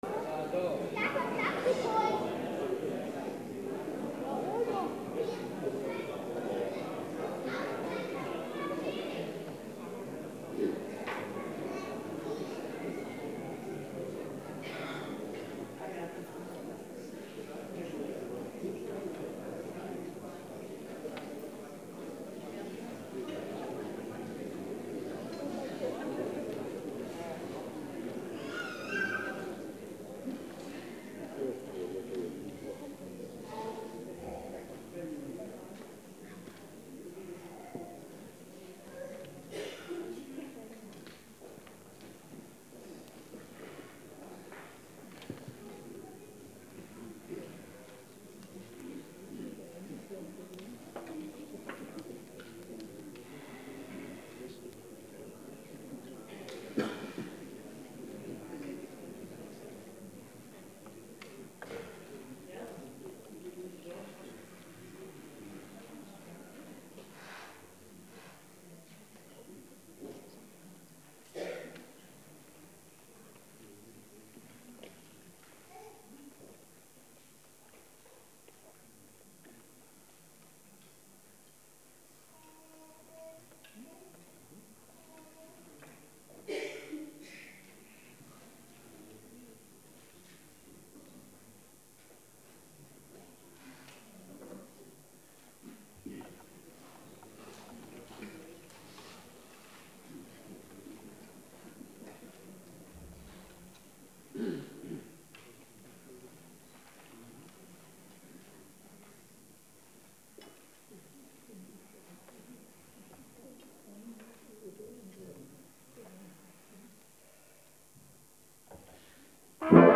Posaunenfeierstunde 2025
Der Posaunenchor Güglingen lädt herzlich zur Posaunenfeierstunde